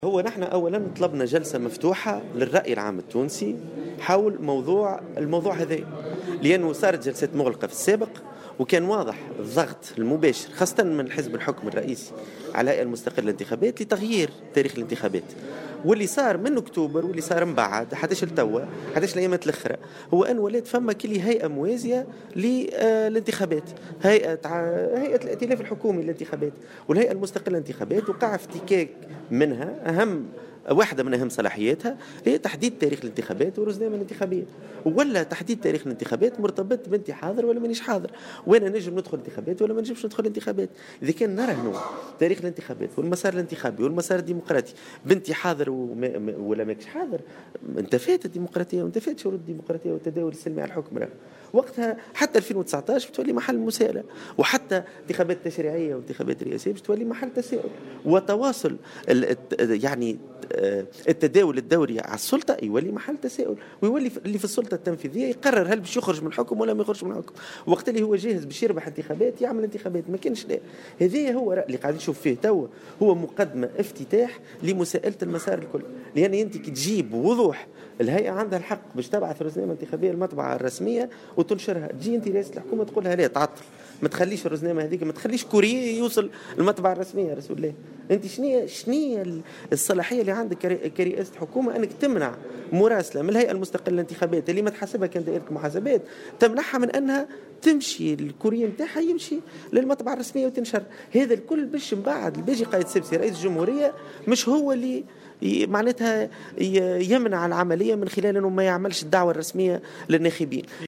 وأضاف اليوم في تصريح لمراسل "الجوهرة أف أم" قبل انطلاق اللقاء التشاوري الذي سيجمع الهيئة بعدد من الأحزاب السياسية، أنه تم الاستيلاء على أهم صلاحيات الهيئة وهي تحديد موعد إجراء الانتخابات و ضبط الرزنامة الانتخابية، مؤكدا أنه على الهيئة أن تتحمل مسؤوليتها أمام الرأي العام.